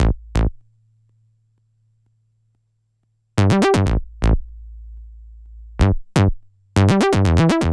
TI124BASS2-L.wav